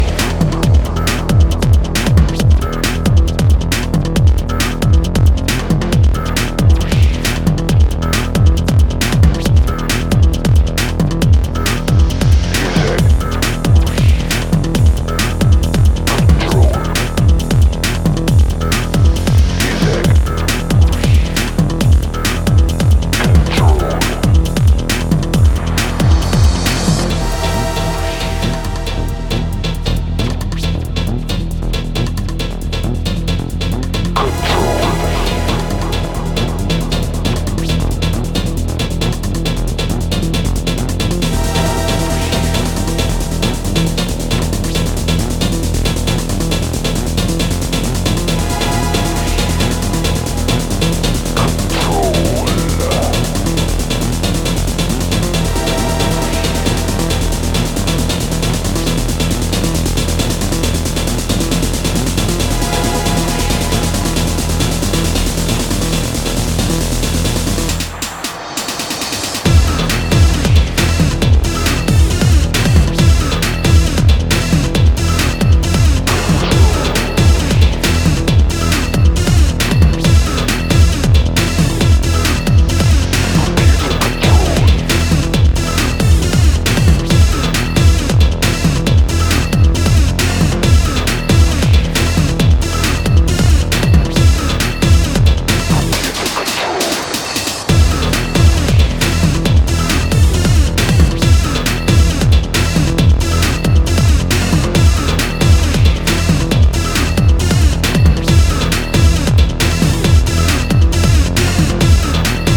electronic music producer